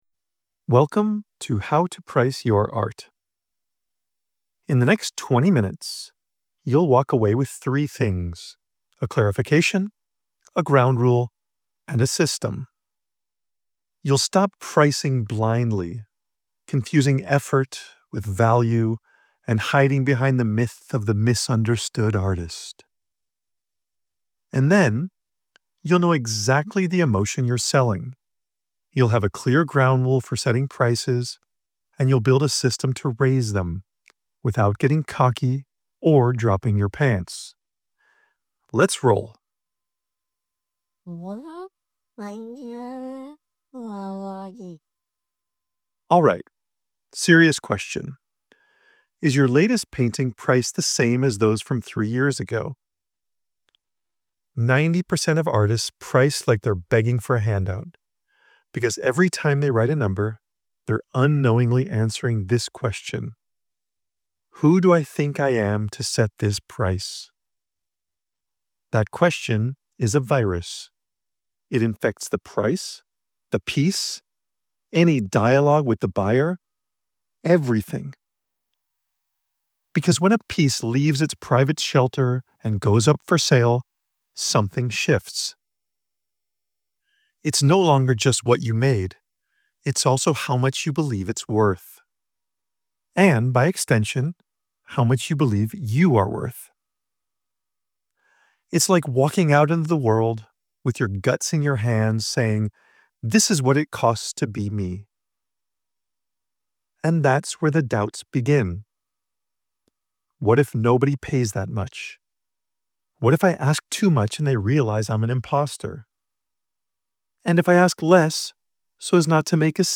(PDF + Audiobook)